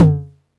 cch_perc_tom_high_tomp.wav